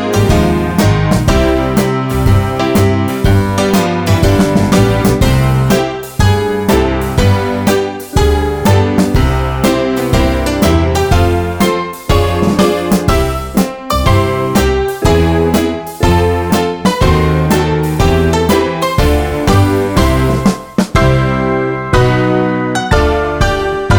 Easy Listening